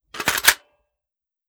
Foley / 5.56 M4 Rifle - Magazine Loading 003.wav